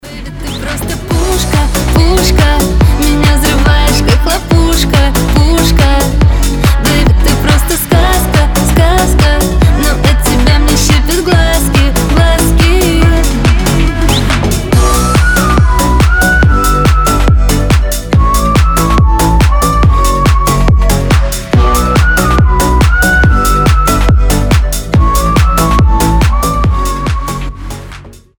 • Качество: 320, Stereo
свист
зажигательные
веселые